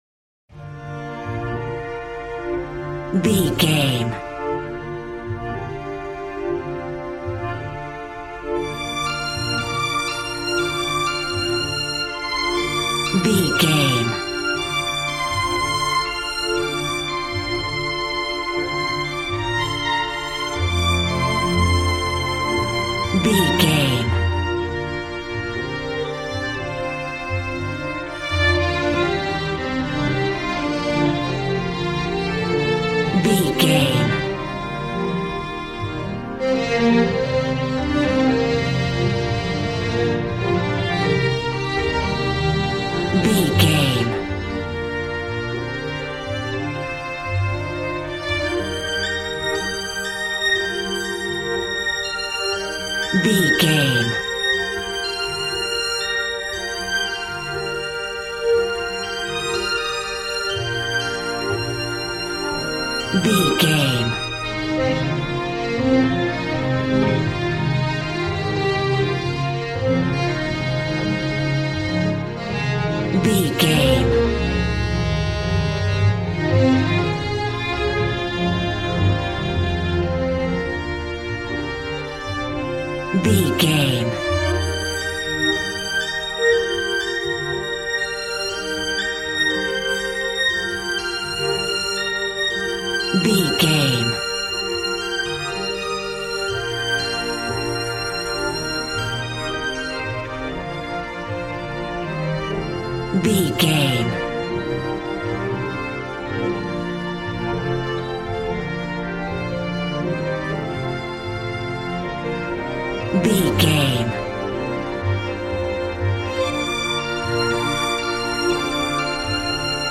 Ionian/Major
joyful
conga
80s